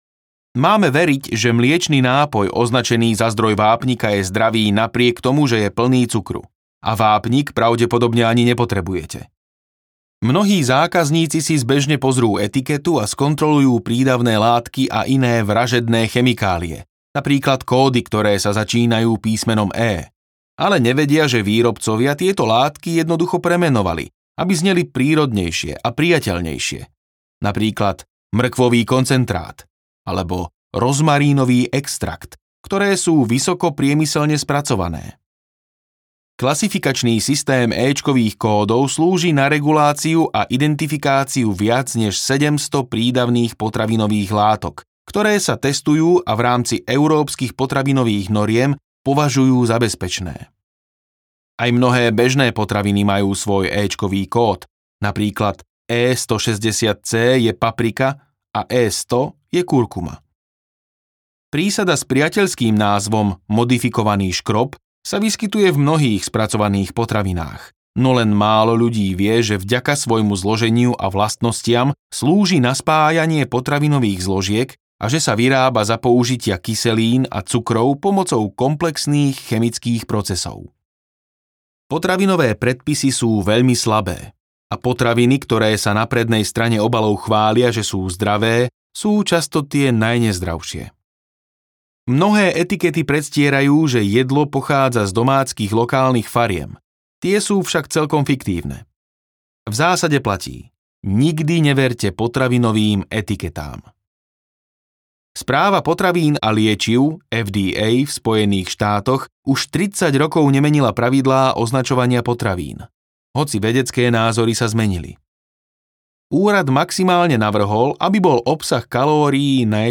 Čo naozaj jeme? audiokniha
Ukázka z knihy